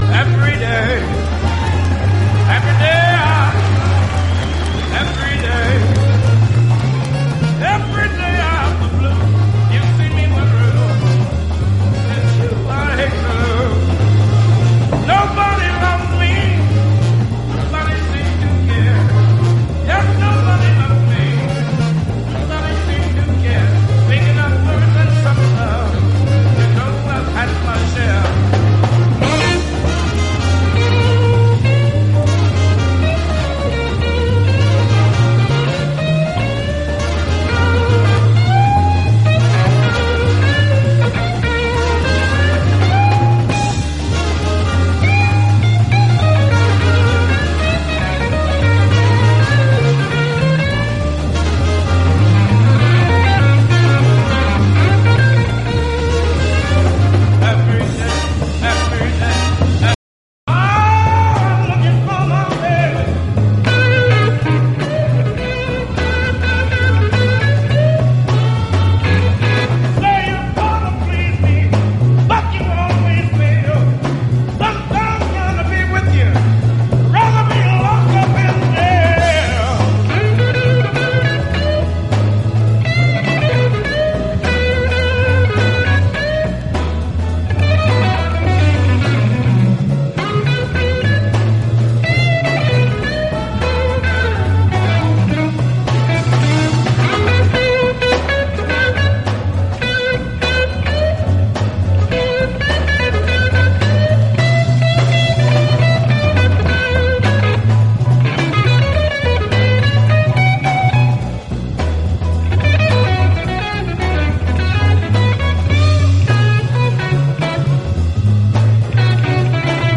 JAZZ ROCK / PROGRESSIVE ROCK
雄大でドラマティックな展開、ファンキーで実験的な演奏が見事です。